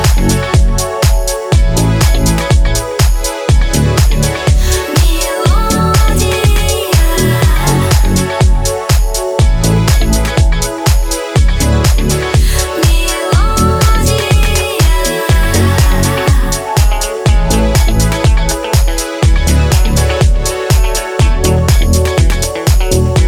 Классный спокойны, хоть и клубный трек